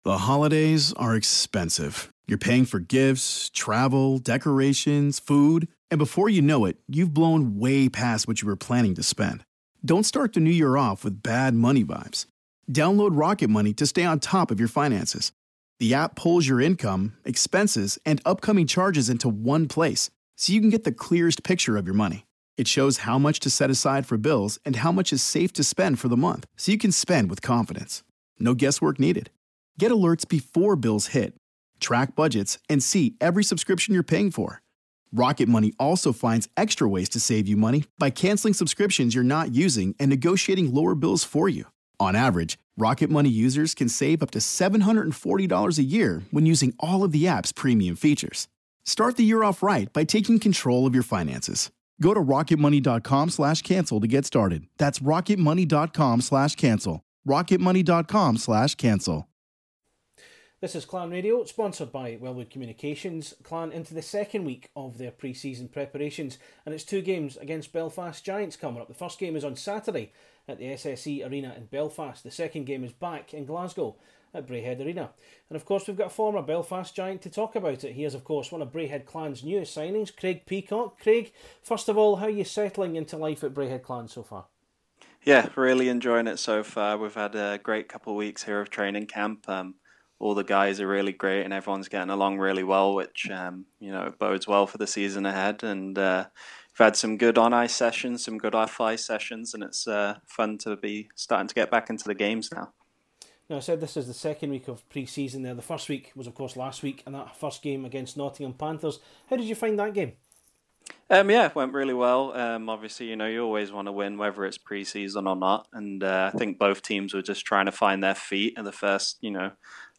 Clan Chat / INTERVIEW